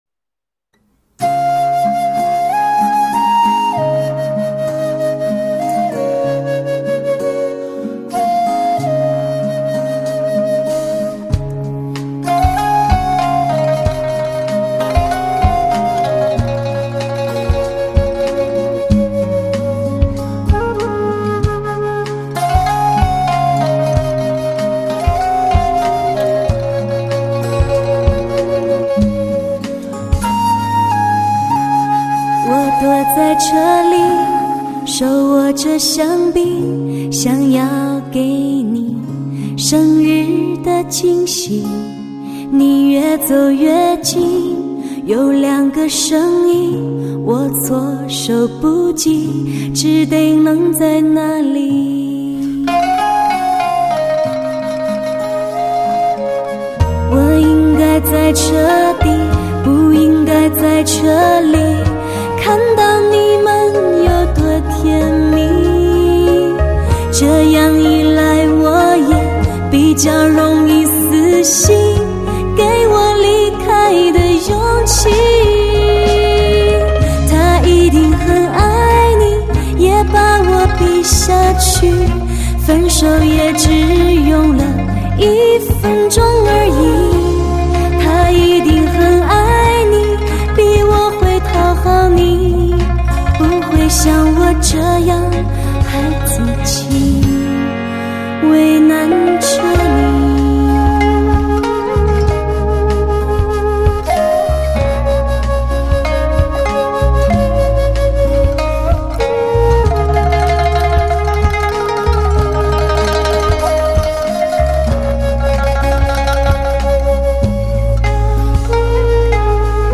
类型: 汽车音乐